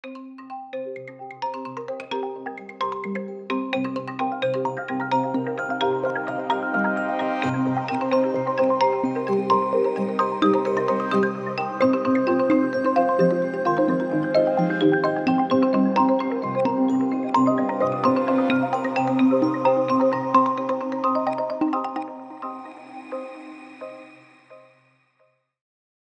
плавные , спокойные
ксилофон